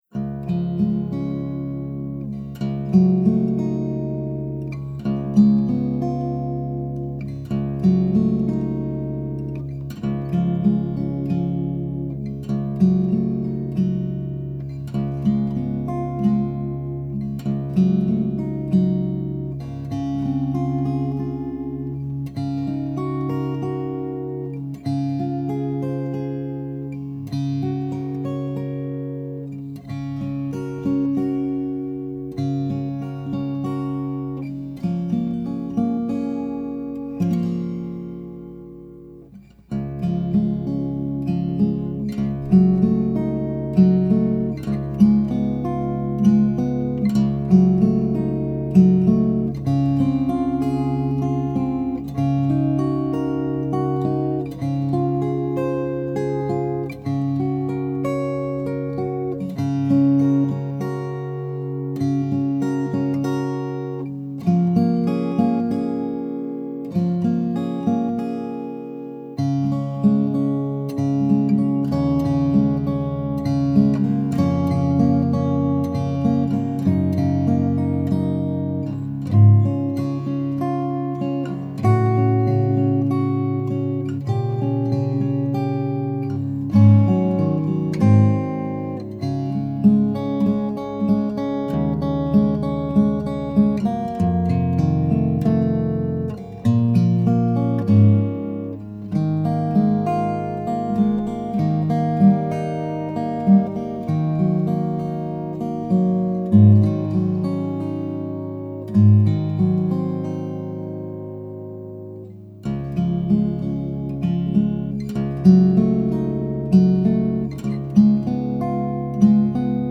Below is a guitar recording for my next arrangement of my song “Alabaster Seashell.”
Alabaster Seashell Guitar arrangement in progress
as-guitar-mix-7-reverb.mp3